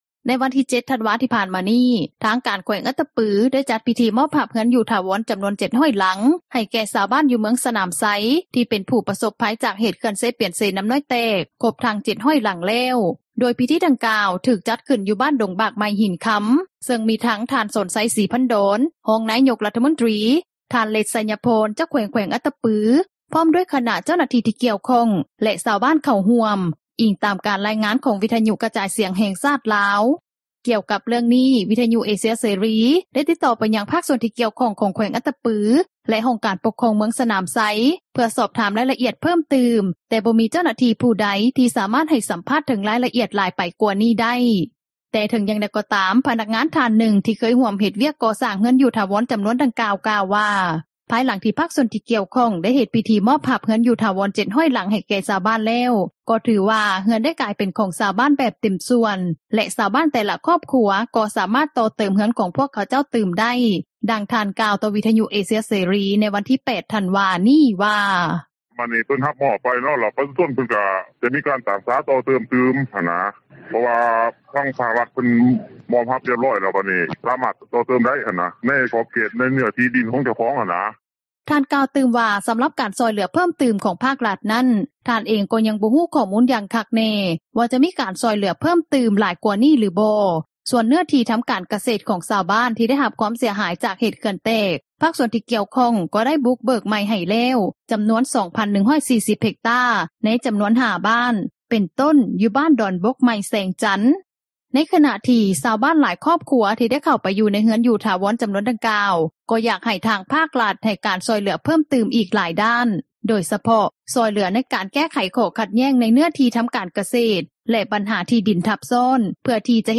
ນັກຂ່າວ ພົລເມືອງ
ດັ່ງຊາວບ້ານ ທ່ານນຶ່ງ ກ່າວວ່າ:
ດັ່ງຍານາງ ກ່າວວ່າ: